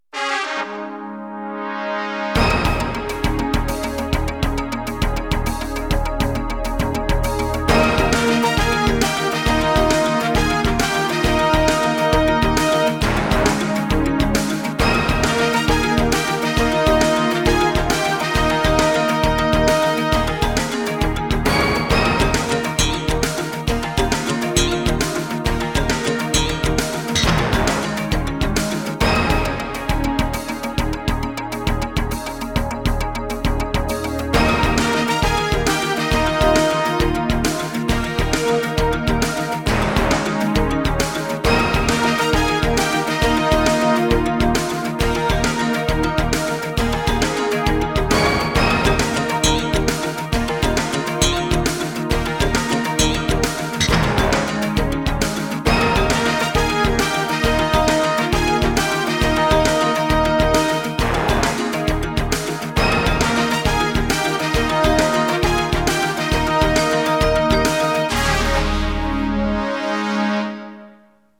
Sintonia del programa